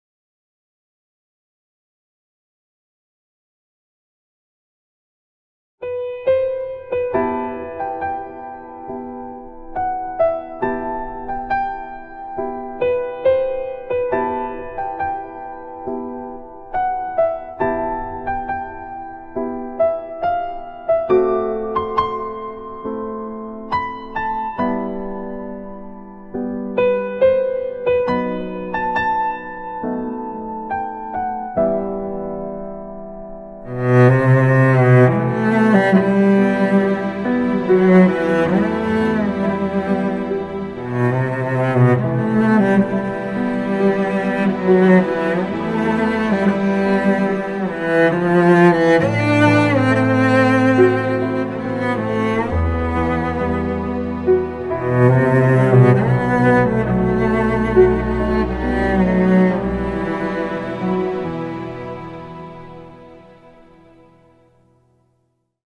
سکانس پلانی از وضعیت زندگی مردم در منطقه سیل‌زده تازه آباد آق‌قلا، نوروز 98